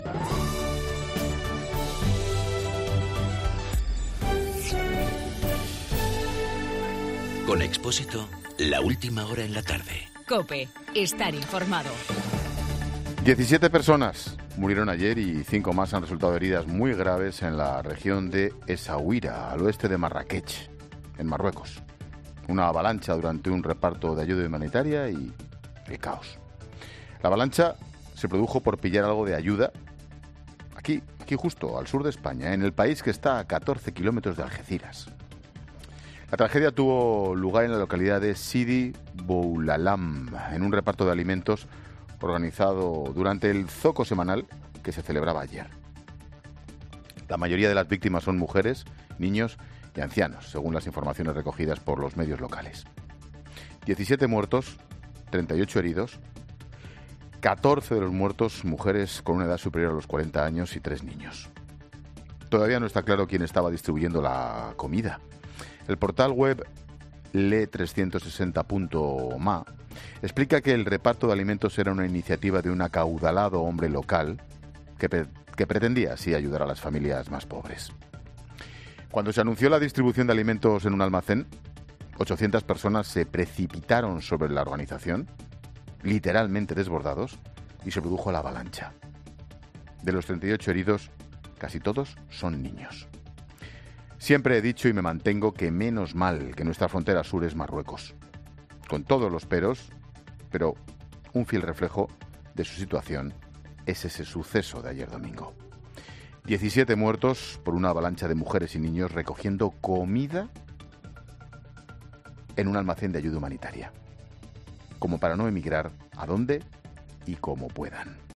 AUDIO: El comentario de Ángel Expósito tras la avalancha sufrida en Marruecos durante un reparto de comida humanitaria.